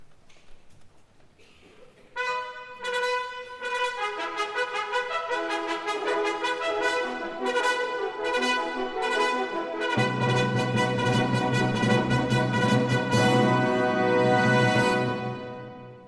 Ejemplo de corchea y dos semicorcheas en una pieza para orquesta